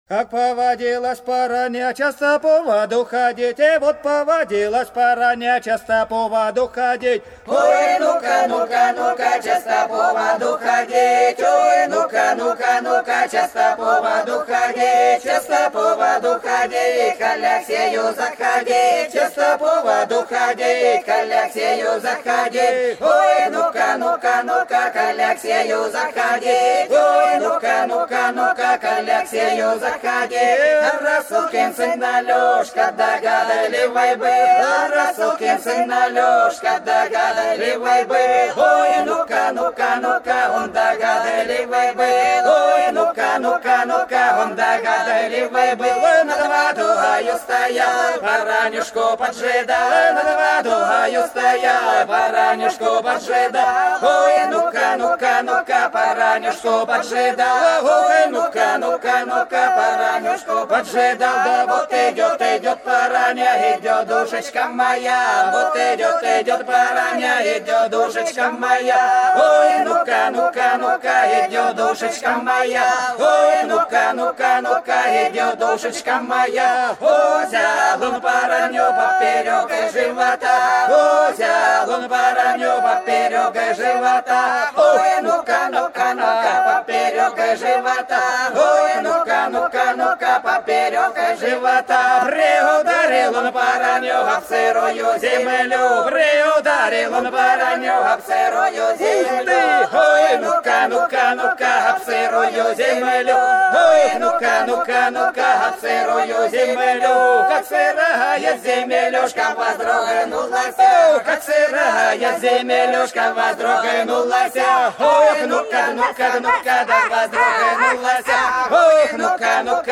Долина была широкая (Поют народные исполнители села Нижняя Покровка Белгородской области) Как повадилась Параня часто по воду ходить - плясовая